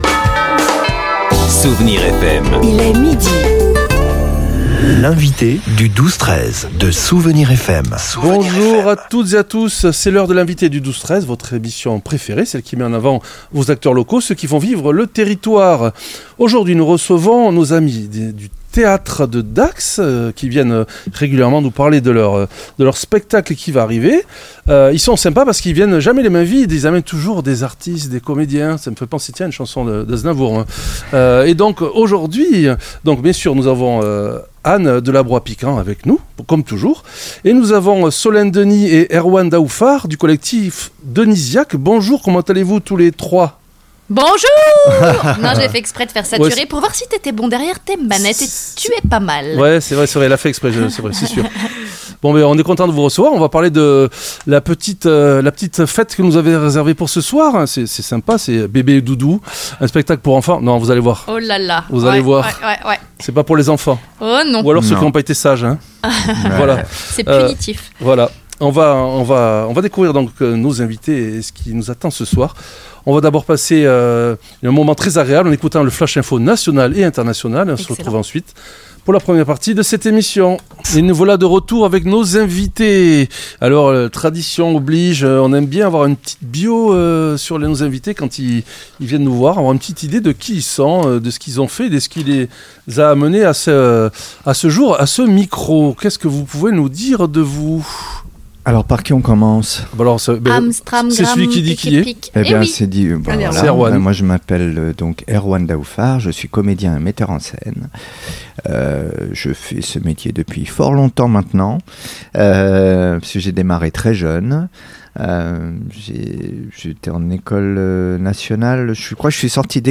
L'entretien a mis en lumière l'utilisation originale de micros HF comme un outil de cadrage quasi-cinématographique, permettant de capter chaque souffle de cette joute verbale.